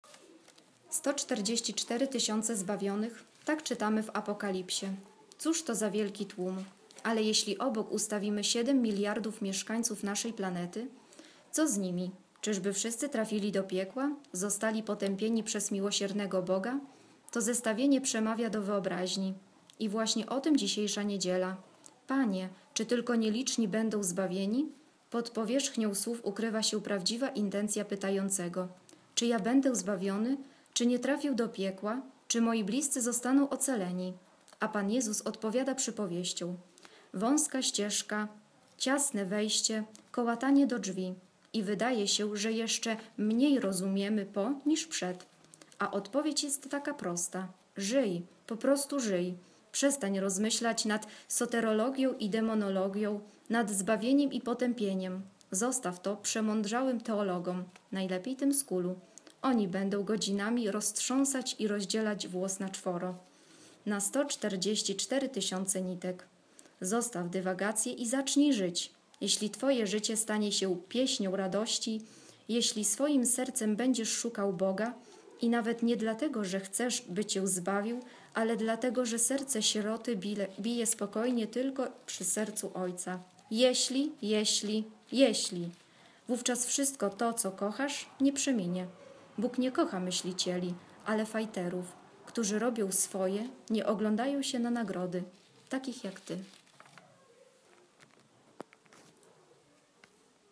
Komentarz do Ewangelii z dnia 21 sierpnia 2016 czyta